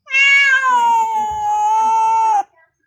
【効果音】猫の鳴き声＃２４「長い声」
長い声で「ミャオォォォォォン」と遠吠えのように鳴く猫の鳴き声の効果音素材です。...